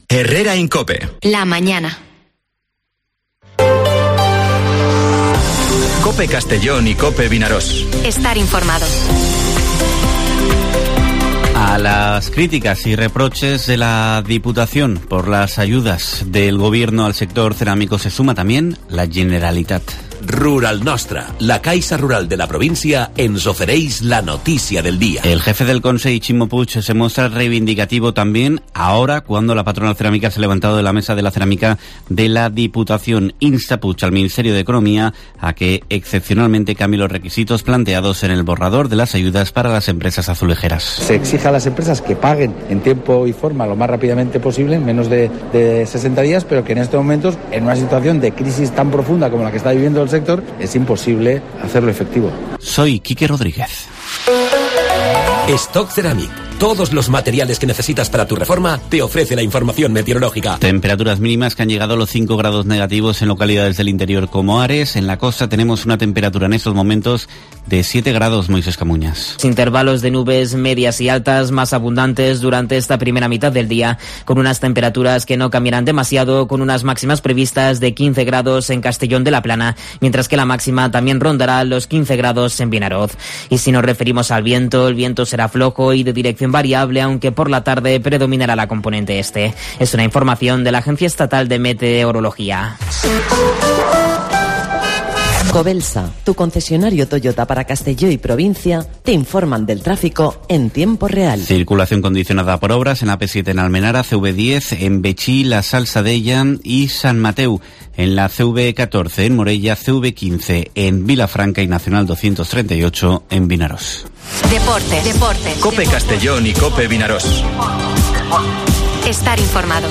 Informativo Herrera en COPE en la provincia de Castellón (16/02/2023)